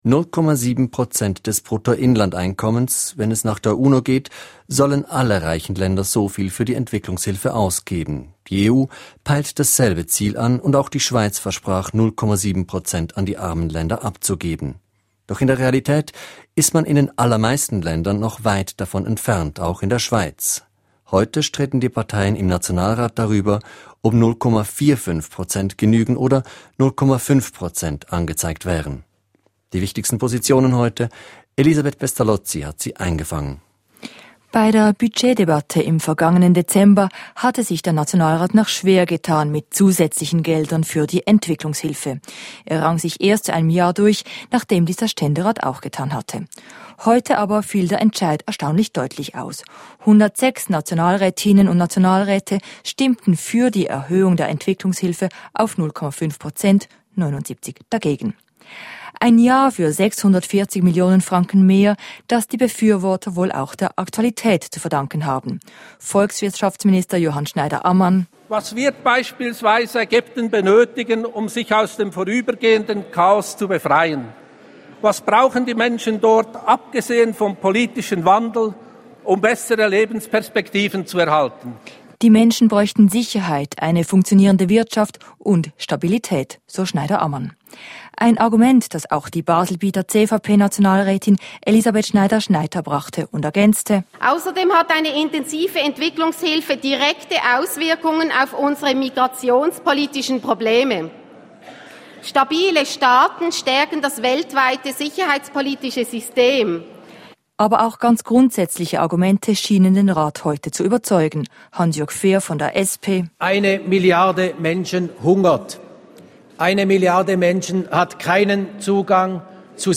Der Nationalrat erhöht die Entwicklungshilfegelder in den kommenden Jahren und ist damit einverstanden, dass die Entwicklungshilfe bis 2015 bei 0,5 Prozent des BIP liegen soll. Bericht und Einordnung.